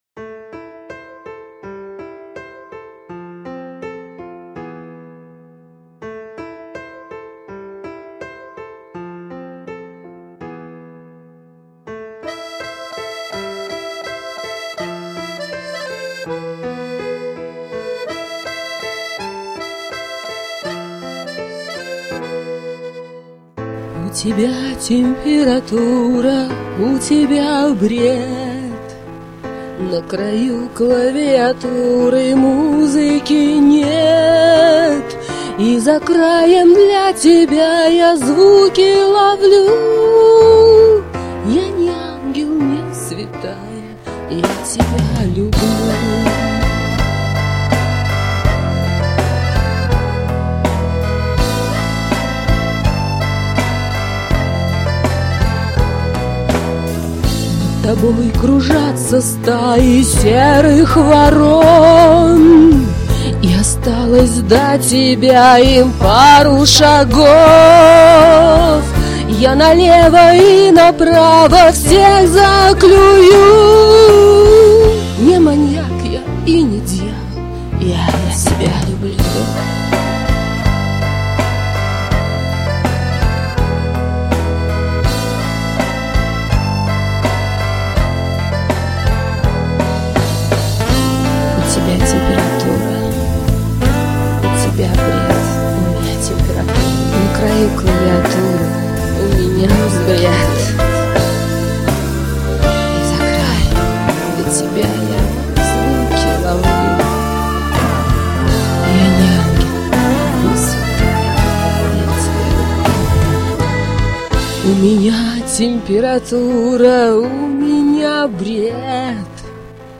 только запись зашкаливает, жалко((((
Знаю про зашкал... не стала исправлять... мой выбор!